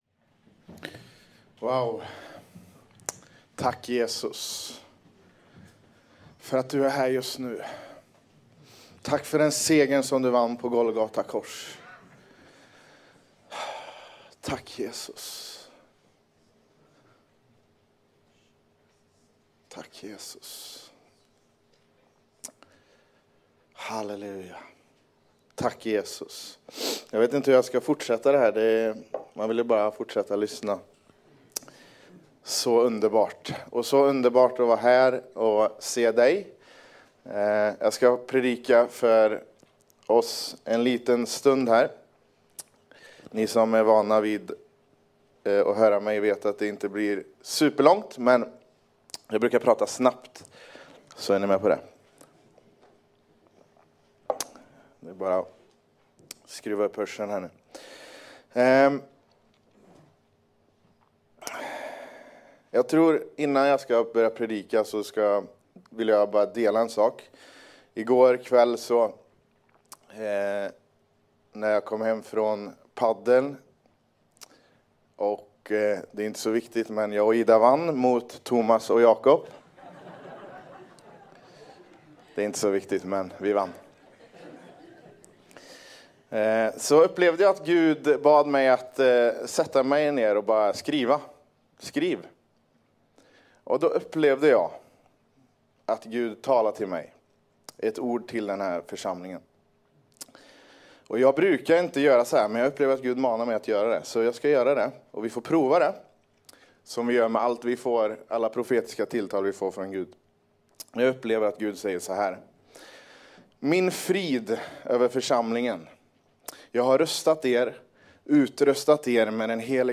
Kören sjunger.